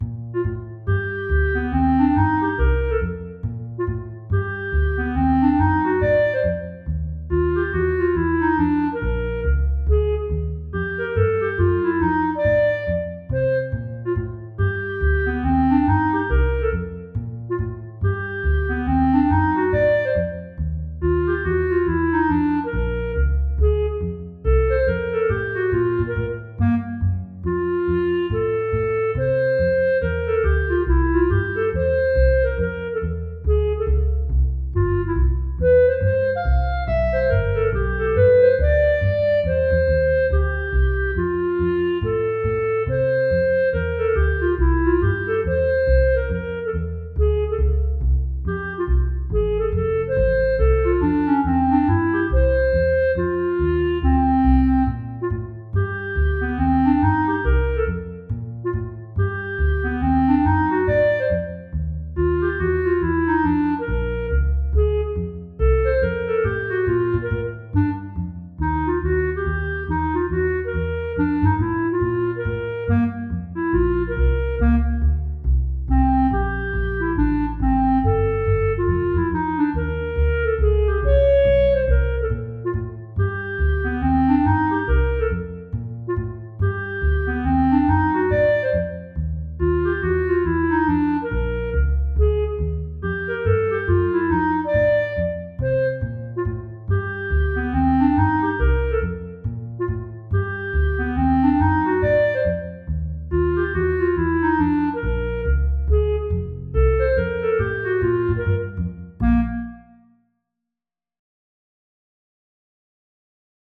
All the instrumental works are recorded through Finale: notation software that gives me the sound of virtually any instrument, from simple piano to a symphony orchestra, and astonishingly lifelike.
Here is another swing thing that I wrote for two of my instrumentalist friends.
Clarinet and cello